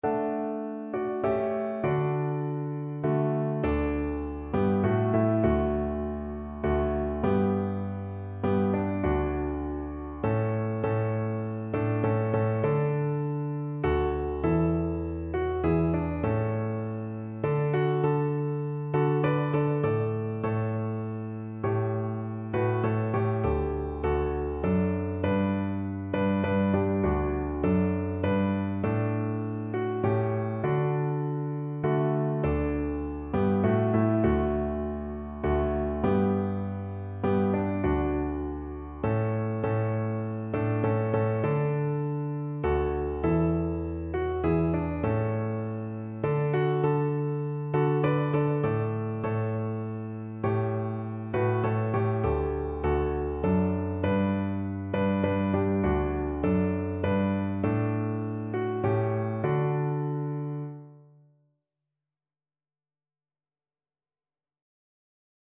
Christian
A3-G4
3/4 (View more 3/4 Music)
Classical (View more Classical Cello Music)